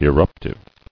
[ir·rup·tive]